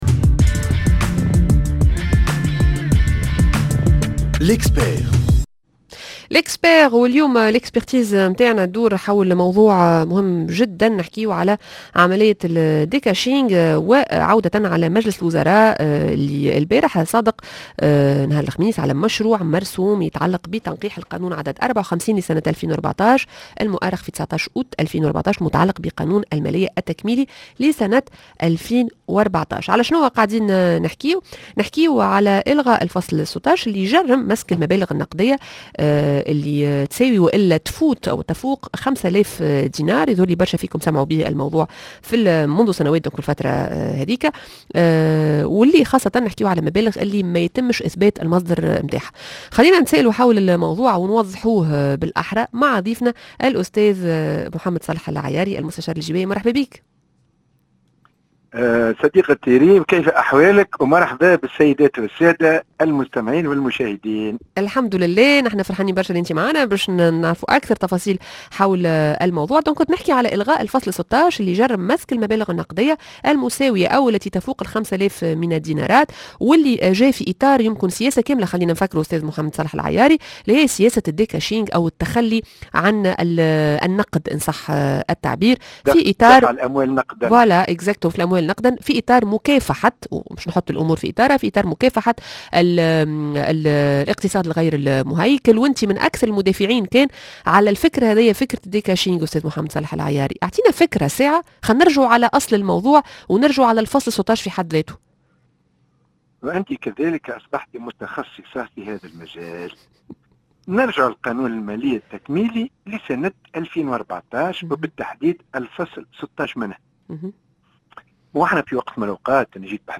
التفاصيل مع المستشار الجبائي